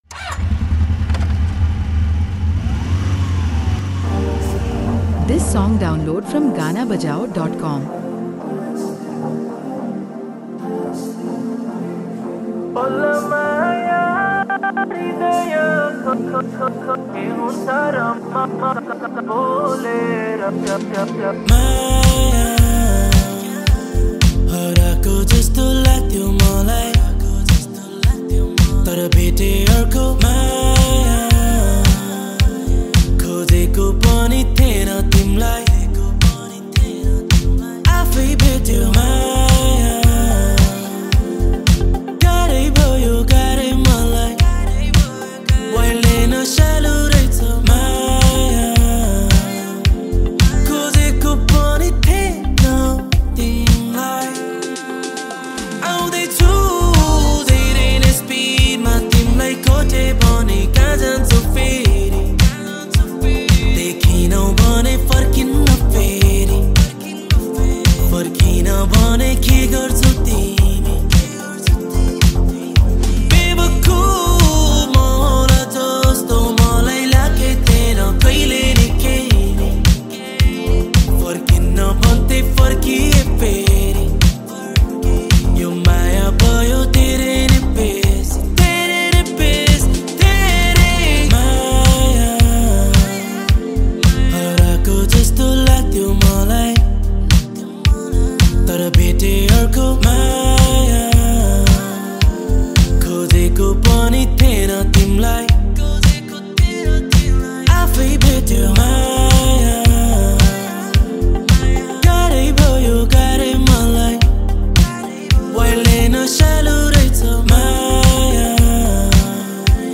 # Nepali Hiphop Song